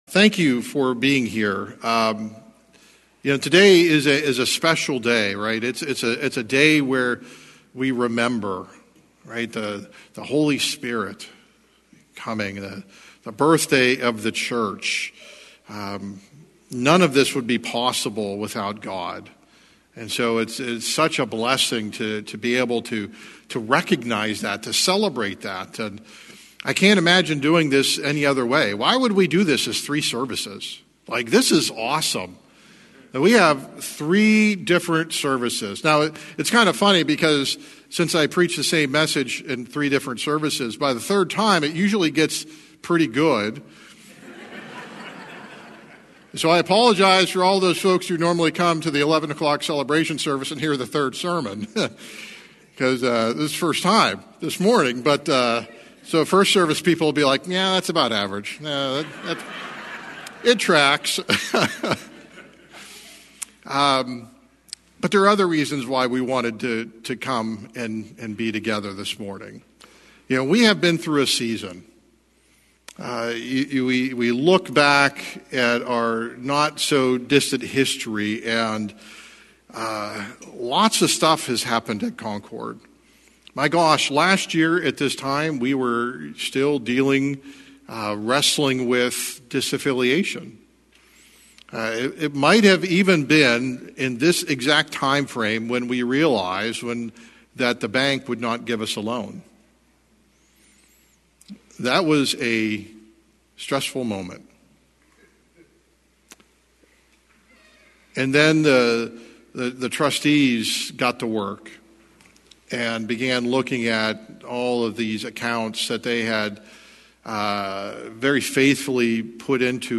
Pentecost Combined Service